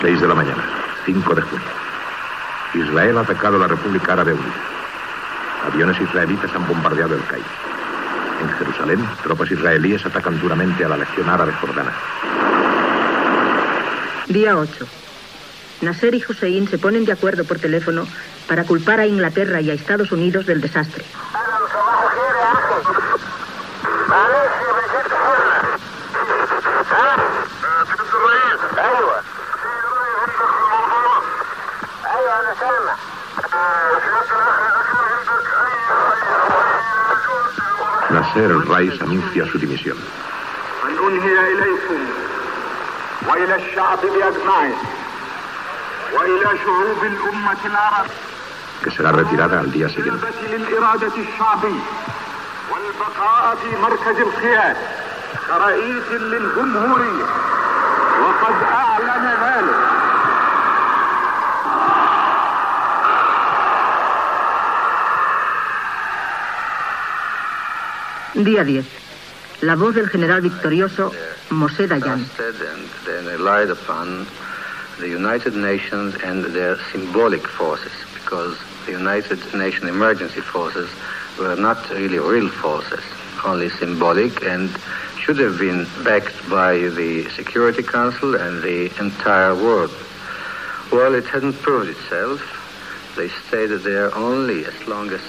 Informatiu
Fragment extret del programa "La radio con botas" de Radio 5 (RNE)